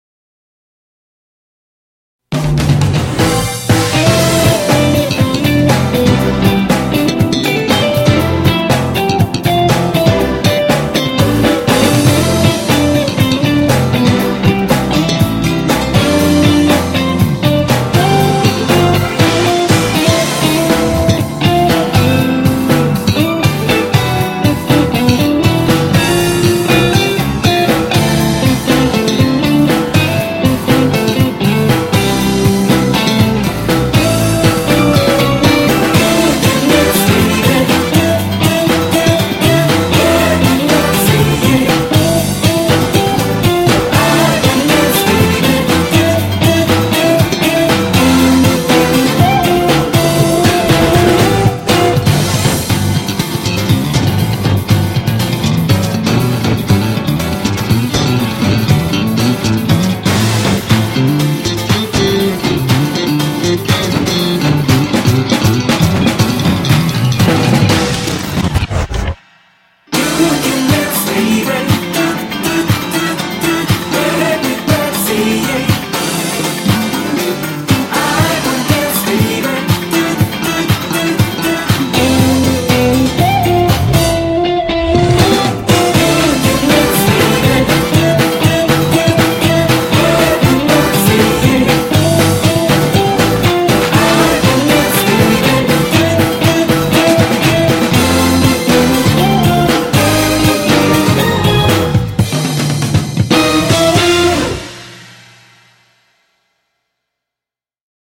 코러스가 완벽하게 조화를 이룬 곡입니다.